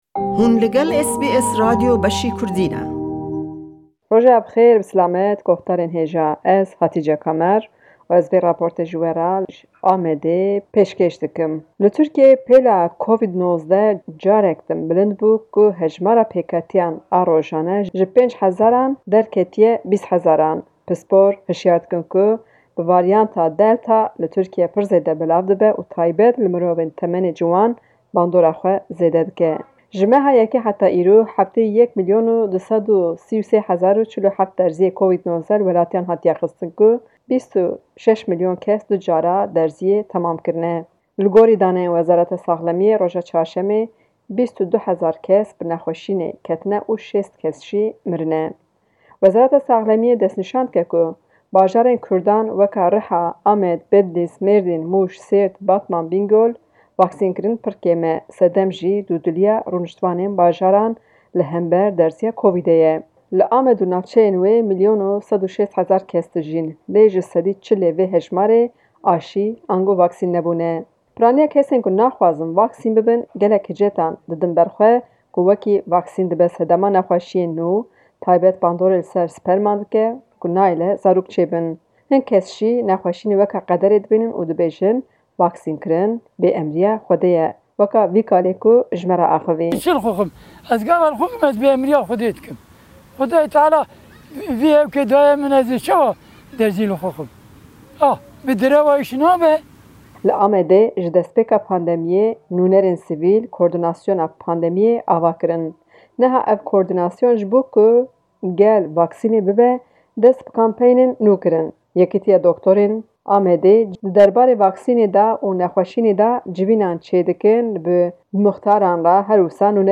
Di raporta vê heftê ji Amedê: Di nav vê mehê de li Amedê hejmara pêketiyên korona zêde bûne, neha her rojê di dora 600î runiştvan bi nexweşina Covid-19 dikevin. Li seranserê Tirkiye 71 milyon doz vaksin hatiye kirin lê bajarê Kurdan ev rêje pir kêm e. Li Amedê rêjay vaksinê ji sedî 40 e. Dudiliyek heye di nav gel de li hember vaksînê.